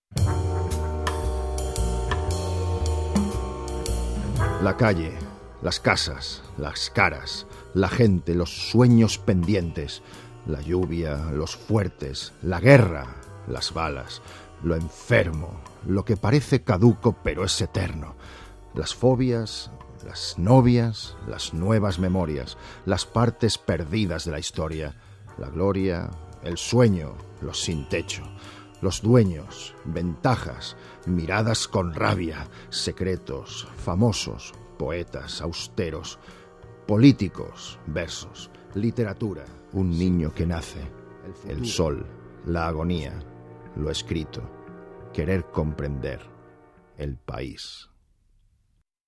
Voz masculina grave con mucha personalidad.
Sprechprobe: eLearning (Muttersprache):
Warm and masculine voice, very versatile both for dramatic and comedy roles.